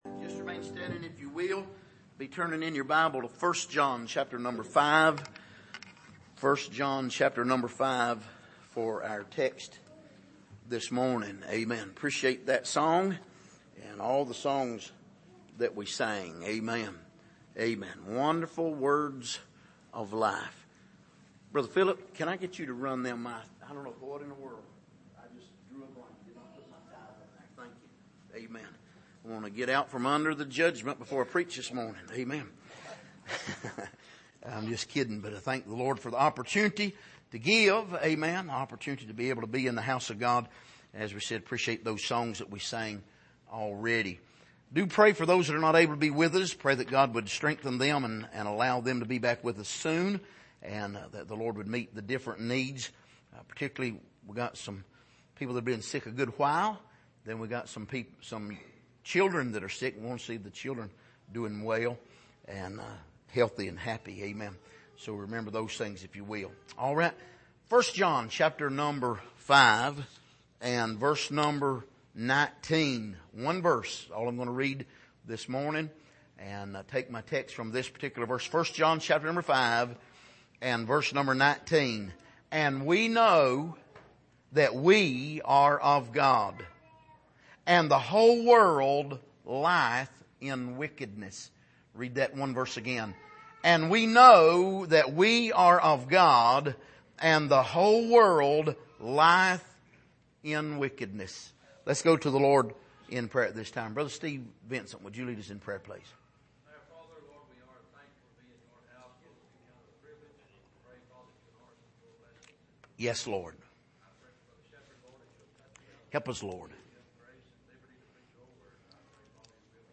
Passage: 1 John 5:19 Service: Sunday Morning